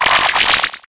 Cri_0871_EB.ogg